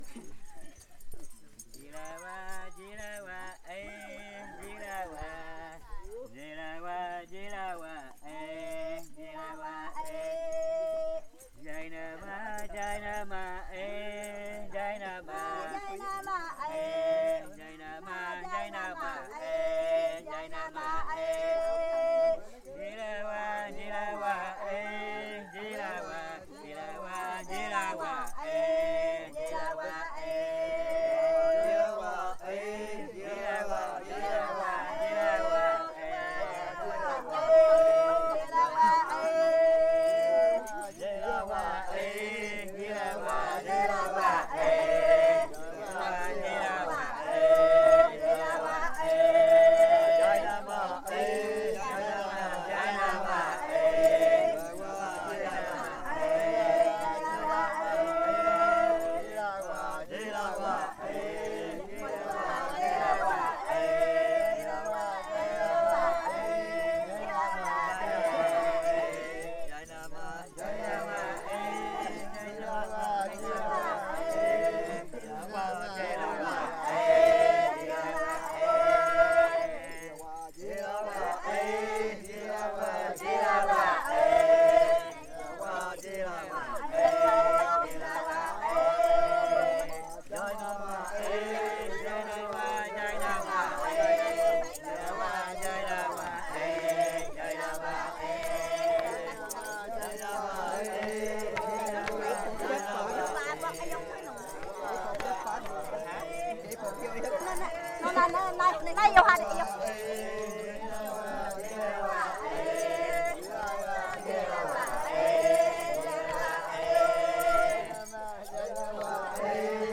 As festas katukina, nas quais apenas pessoas casadas podem cantar, são sempre lideradas por um casal, onde o esposo lidera os homens e a esposa lidera as mulheres.
Os cantos são entoados de noite no pátio da aldeia, os homens formando um alinhamento paralelo ao alinhamento das mulheres, de modo que os cônjuges ficam frente a frente, sem se encostarem, separados apenas pelas tiras de buriti das máscaras usadas pelos homens.
Barakohana 2015. Canto de encerramento.mp3